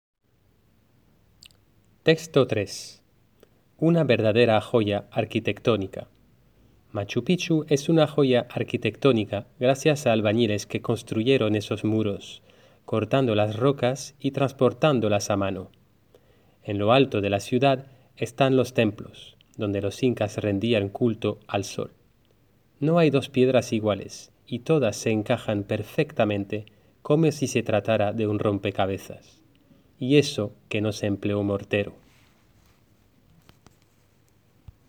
Lectura del texto por el profesor: